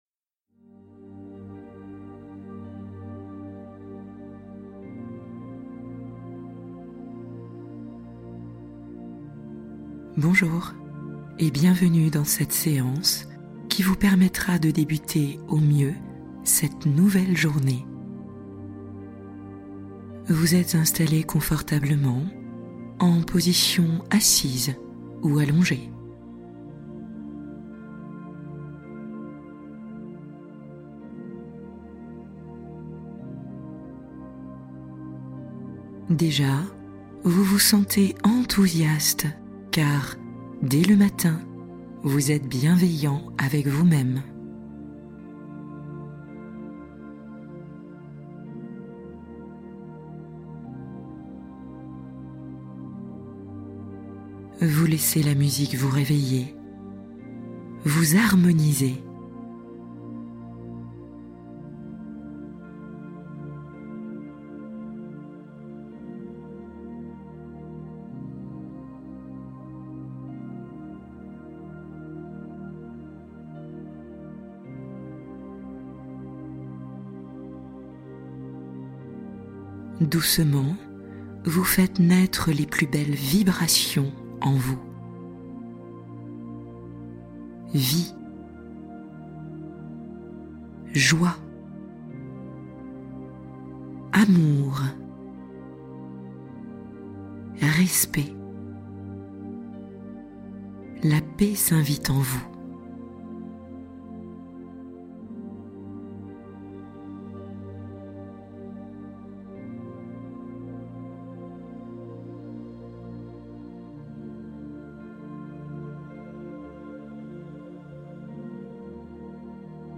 Commencez la journée dans une paix totale | Méditation matinale douceur